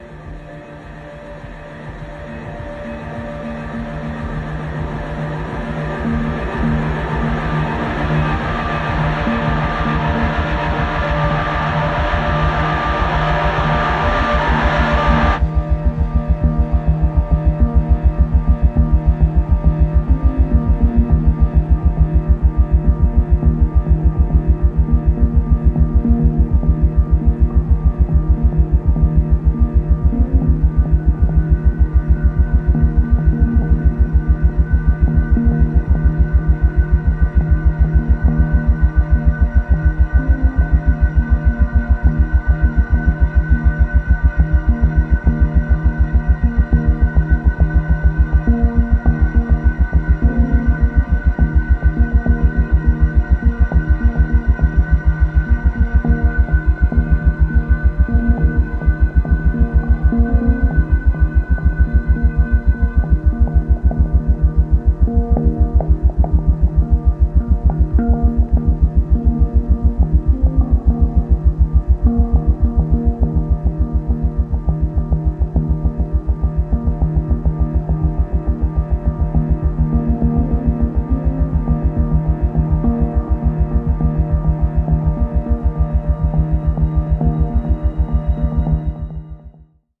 Filed under: Ambient